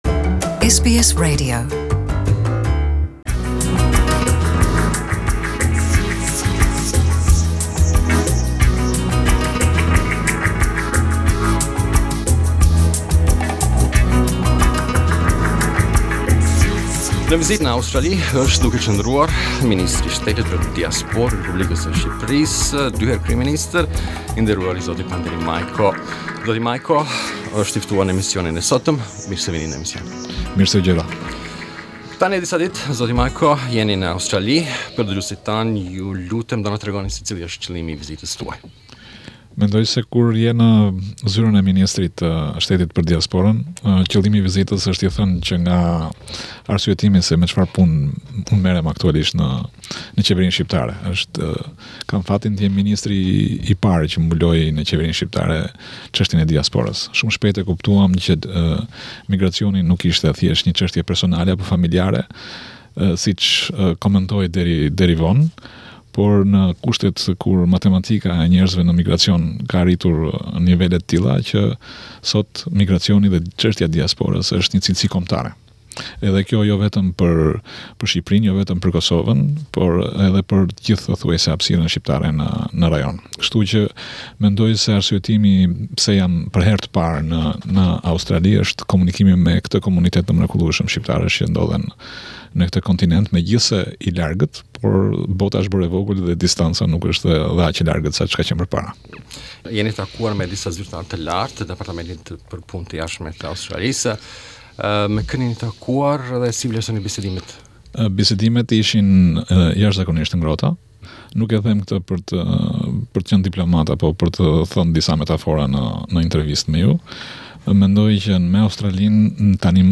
The Minister took time from a busy agenda to visit the premises of Radio SBS and participated in an interview with the Albanian program where he spoke about his visit, the government’s objectives in relation to diaspora and his first impressions about Australia.